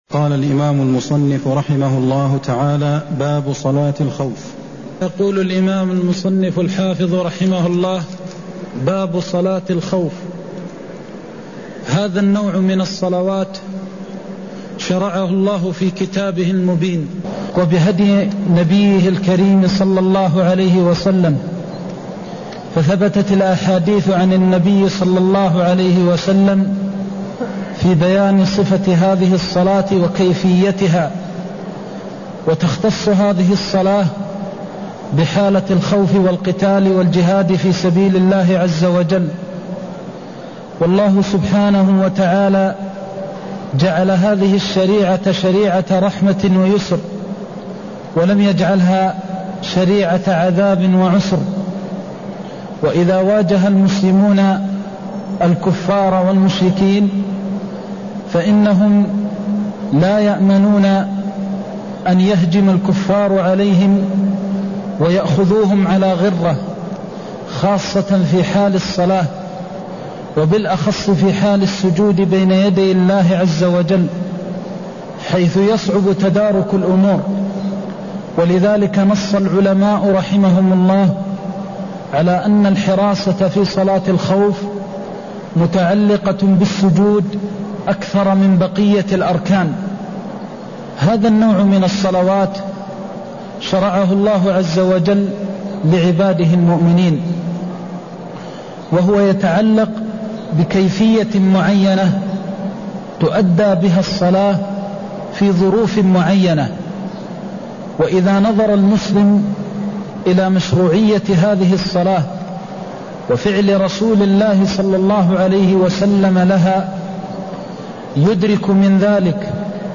المكان: المسجد النبوي الشيخ: فضيلة الشيخ د. محمد بن محمد المختار فضيلة الشيخ د. محمد بن محمد المختار يصلي بكل طائفة ركعة (147)